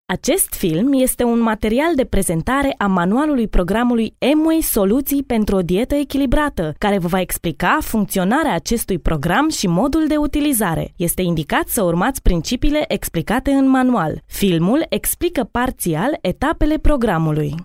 Professionell female voice over artist from Romania.
Sprecherin rumänisch
Sprechprobe: Werbung (Muttersprache):
female voice over artist romanian